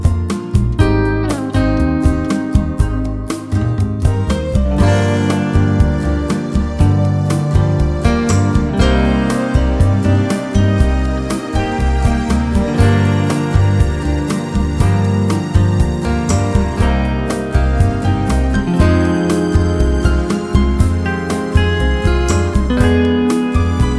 no Backing Vocals Jazz / Swing 4:33 Buy £1.50